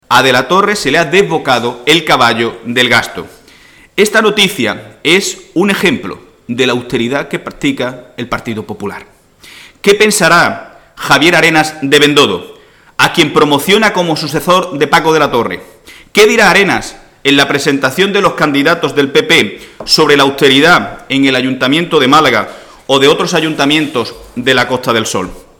(*Incluye audio con declaraciones de Miguel Ángel Heredia)